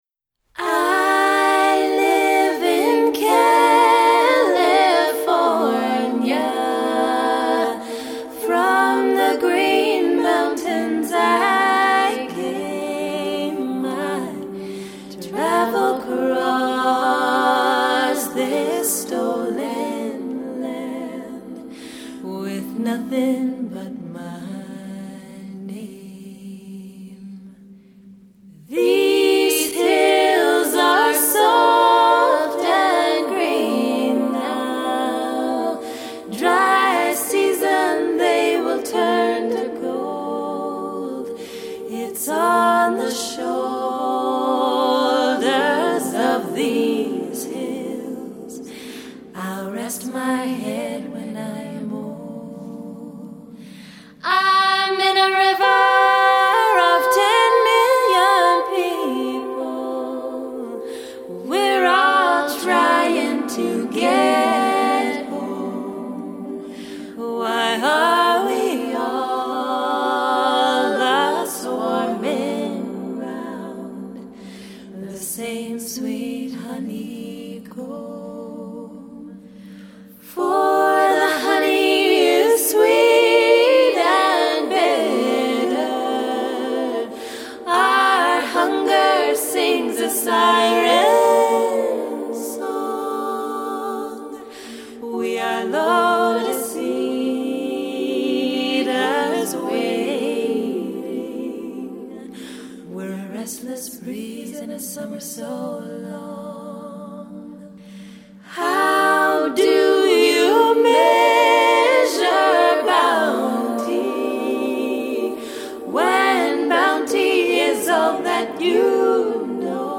body percussion